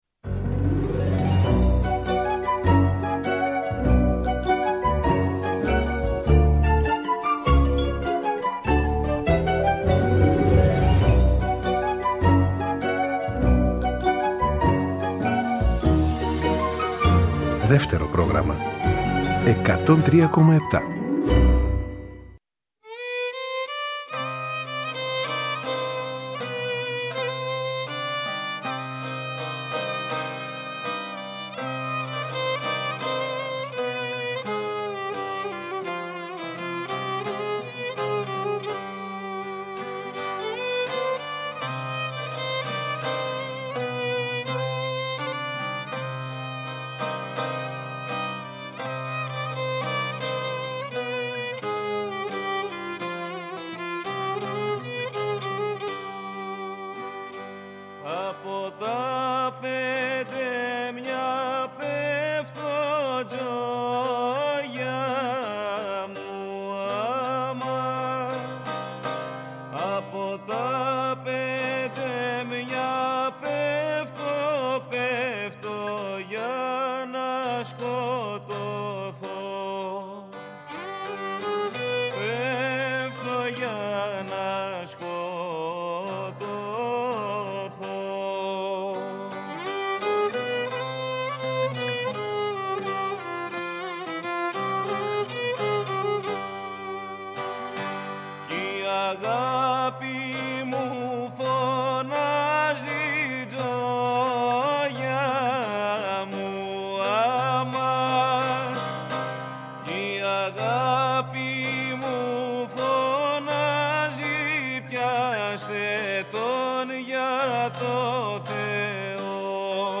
βιολί & τραγούδι
λαούτο
λαούτο & πολίτικο λαούτο
ηχογραφήθηκαν ζωντανά στο στούντιο Ε της Ελληνικής Ραδιοφωνίας
Live στο Studio